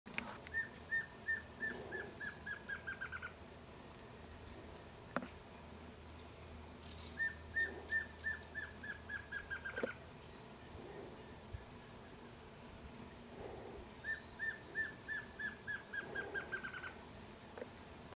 Choquinha-lisa (Dysithamnus mentalis)
Nome em Inglês: Plain Antvireo
Fase da vida: Adulto
Localidade ou área protegida: Parque Federal Campo San Juan
Condição: Selvagem
Certeza: Gravado Vocal